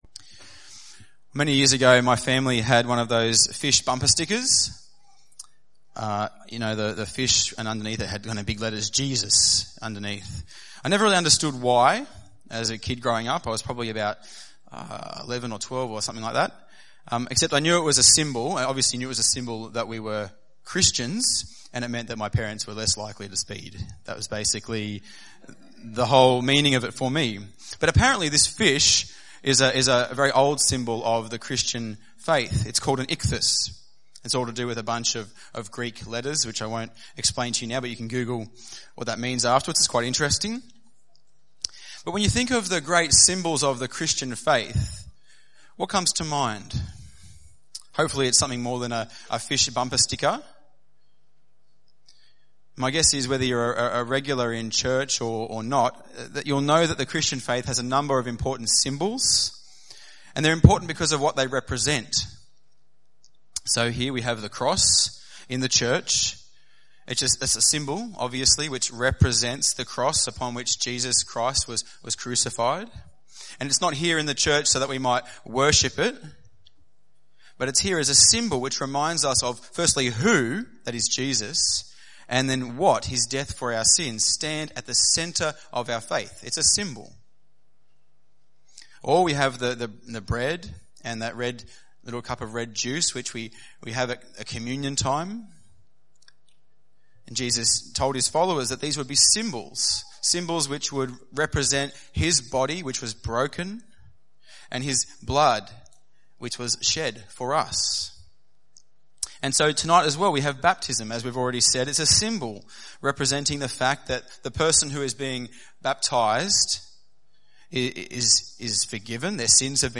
Baptism is one of the great symbols of the Christian faith, representing both the reality of sins forgiven, and the fact that a Christian has died to the old life of sin and been raised to new life with Christ. And our rejoicing tonight, as we witness several baptisms, is also a symbol which represents the fact that God himself rejoices with his angels when sinners repent.